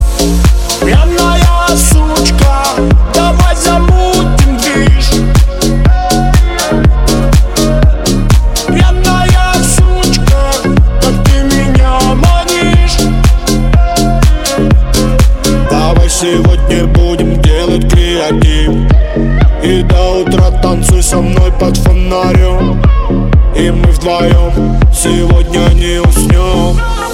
• Качество: 160, Stereo
поп